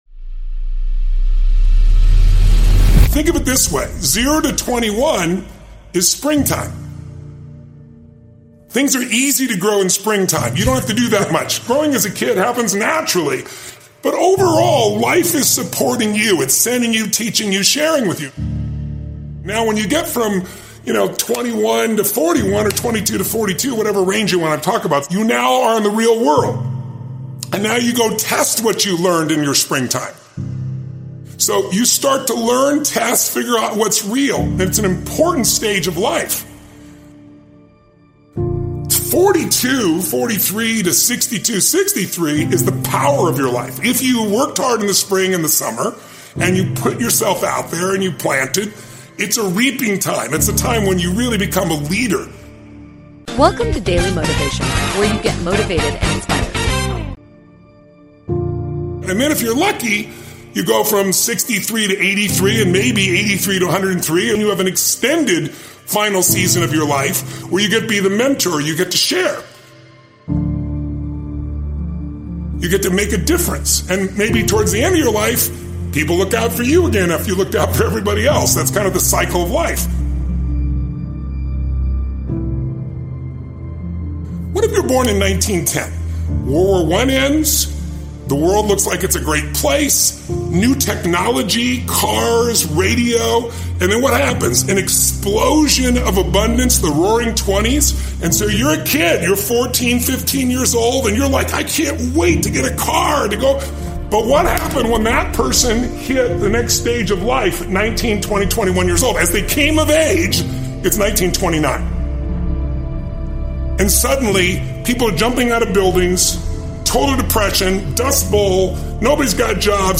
Speakers: Tony Robbins